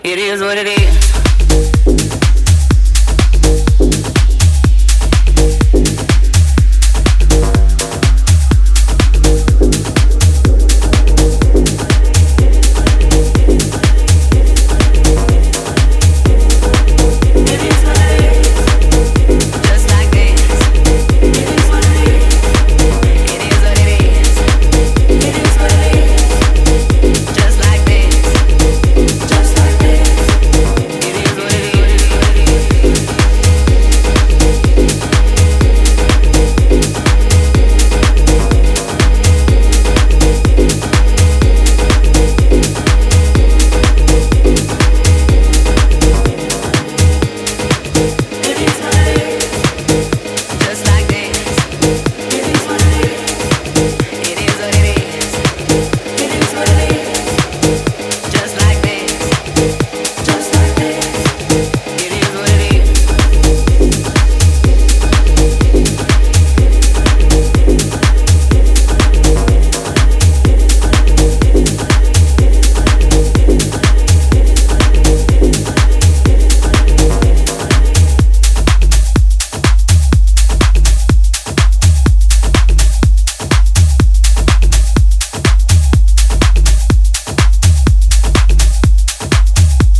ジャンル(スタイル) DEEP HOUSE / SOULFUL HOUSE / TECH HOUSE